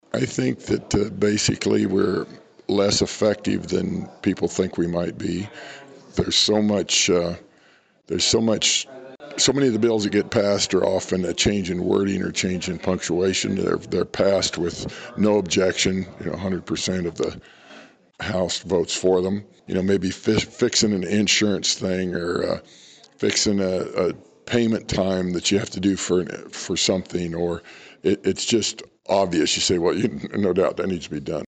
With the end of the 2023 regular legislative session approaching, local lawmakers were able to offer updates on a variety of topics during the latest legislative dialogue at the Flint Hills Technical College main campus Saturday morning.